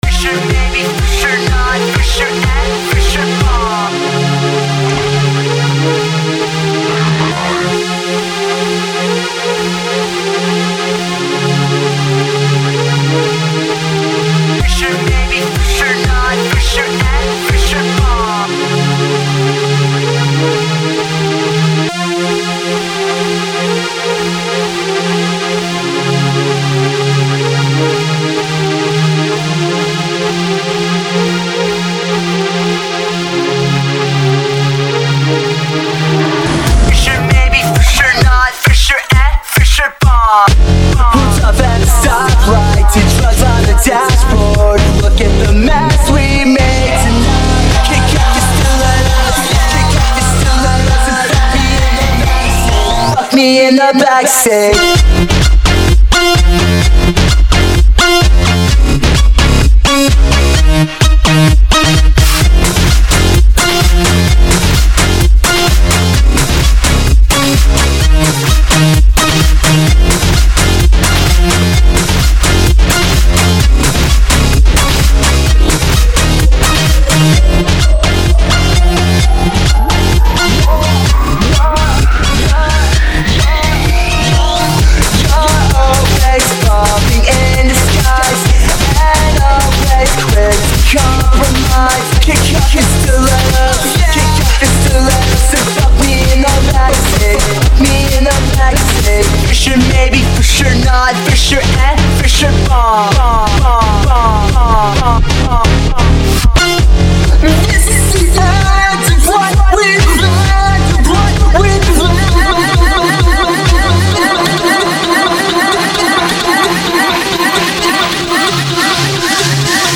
Категория: Electro, Progressive House